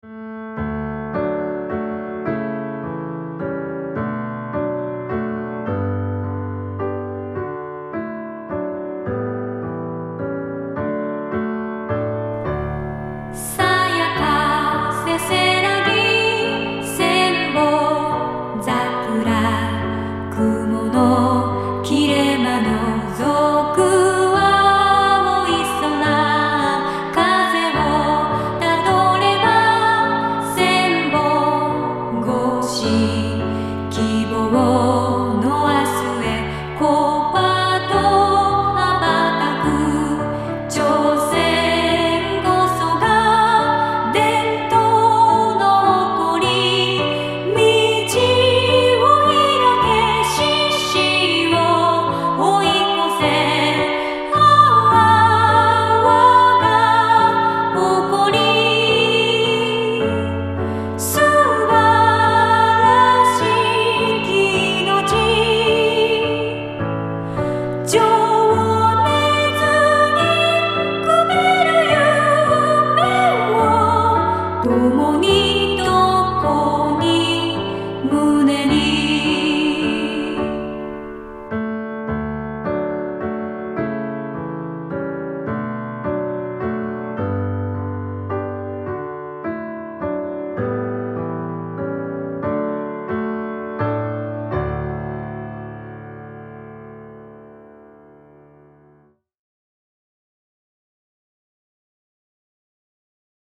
校歌